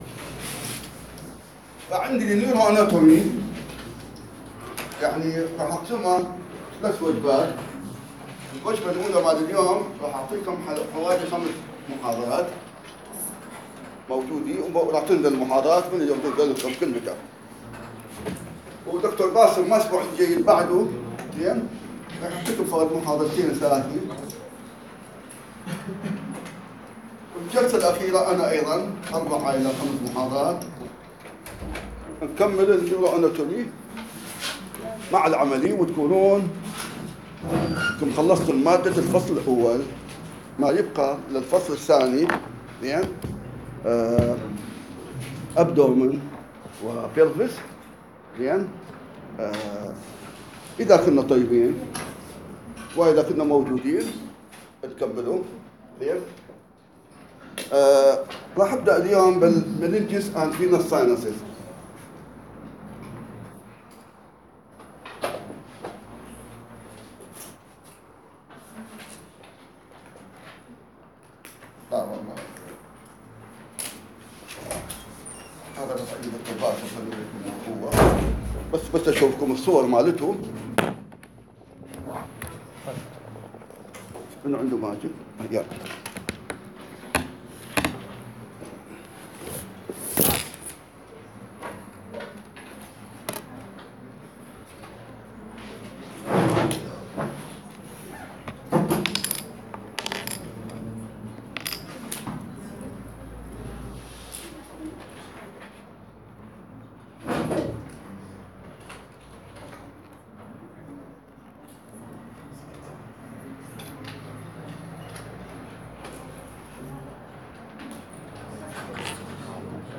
Neuroanatomy voice recording